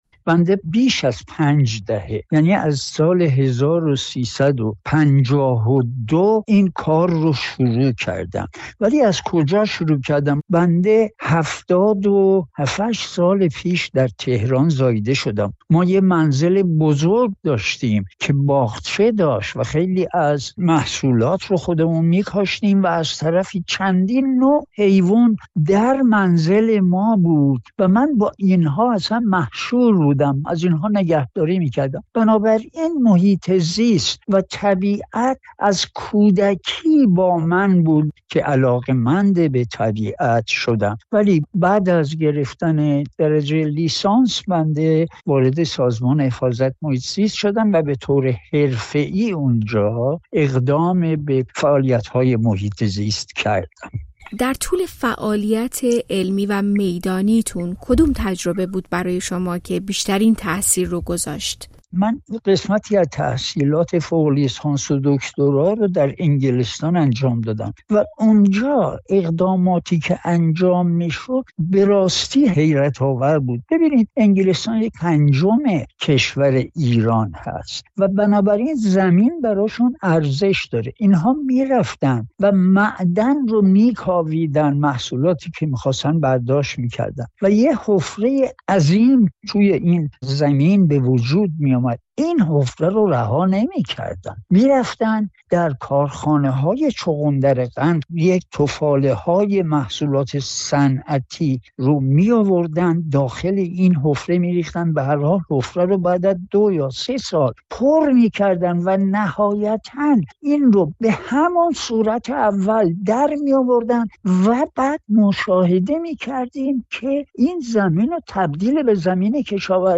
دکتر اسماعیل کهرم به پاس یک عمر تلاش در عرصه حفاظت از محیط‌ زیست ایران مورد تقدیر قرار گرفته و جایزه مهرگان علم را دریافت کرد. او در گفت‌وگو با رادیوفردا بار دیگر در مورد بحران بی‌آبی و نابودی تالاب‌های کشور هشدار داد.